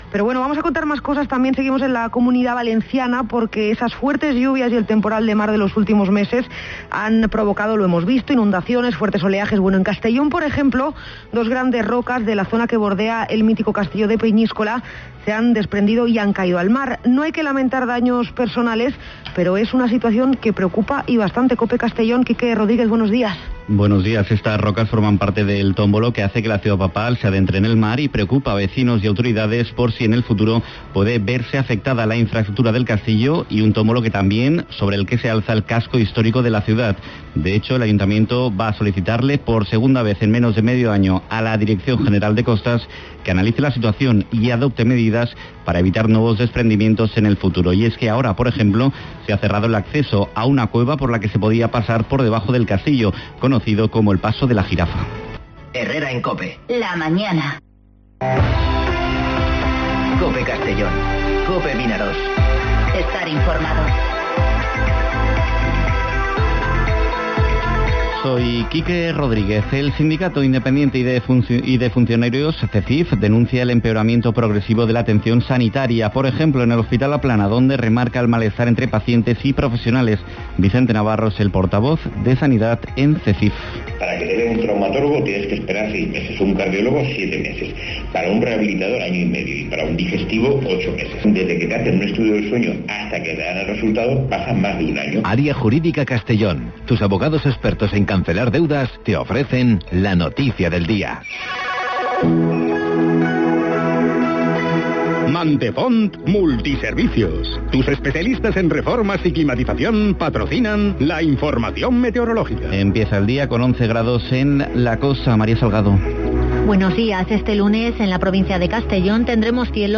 Informativo Herrera en COPE Castellón (09/12/2019)